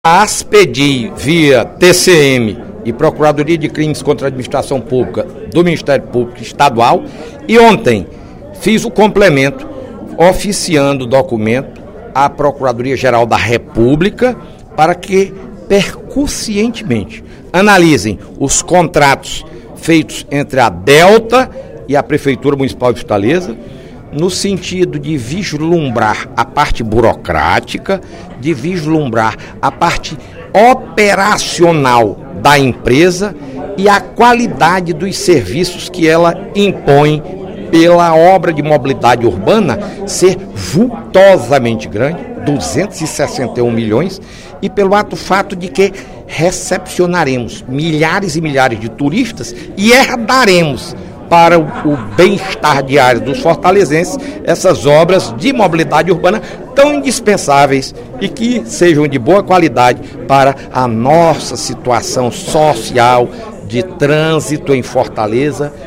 O deputado Fernando Hugo (PSDB) comunicou nesta quarta-feira (25/04), em sessão plenária, que enviou ofício a Procuradoria Geral da República solicitando a fiscalização dos contratos feitos entre a Prefeitura Municipal de Fortaleza e a empresa Delta, apontada como líder de um esquema de corrupção pela Polícia Federal (PF) e pela Controladoria-Geral da União (CGU).